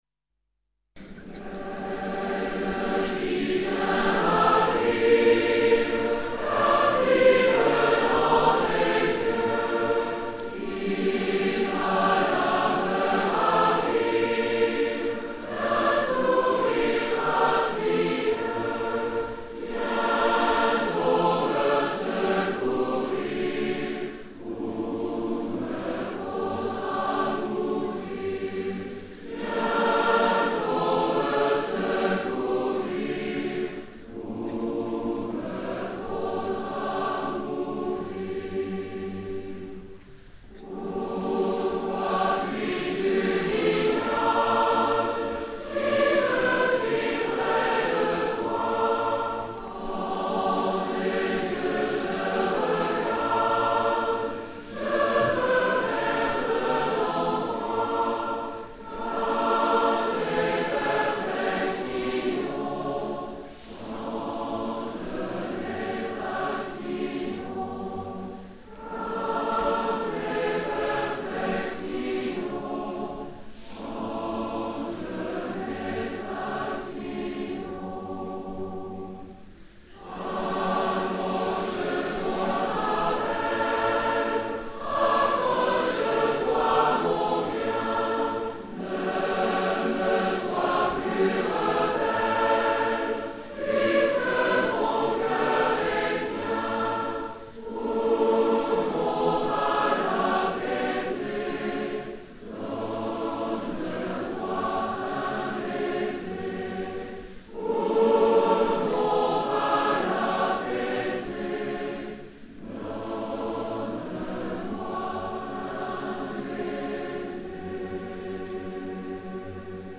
Work for SATB a cappella choir and tambourine ad libitum